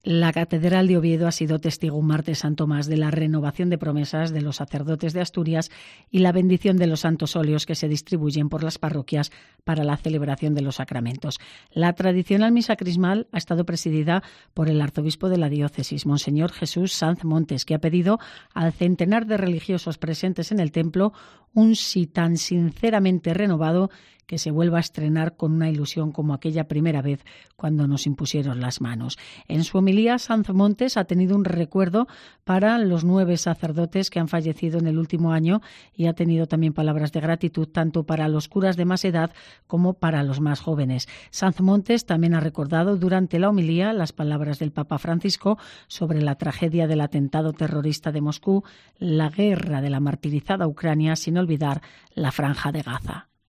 Así hemos contado en COPE la Misa Crismal en la Catedral de Oviedo